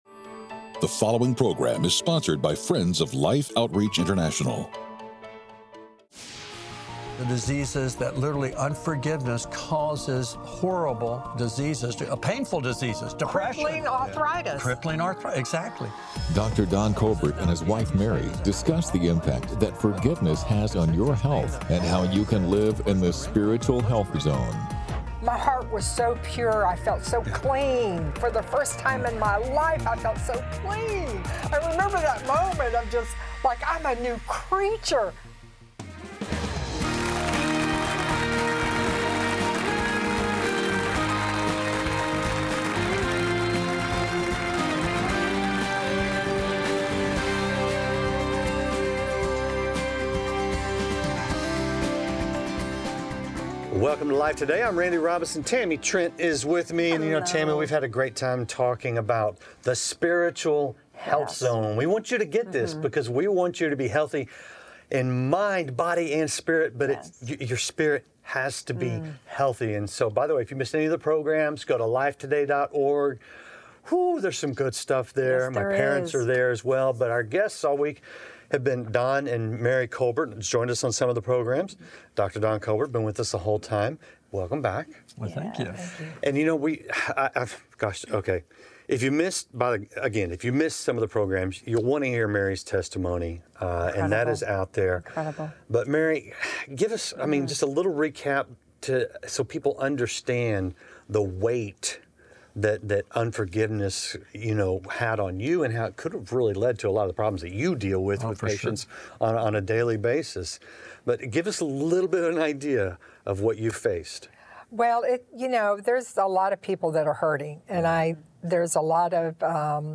A medical doctor explains the physical impacts of forgiveness as his wife shares the life-changing freedom she found by forgiving the one who hurt her the most.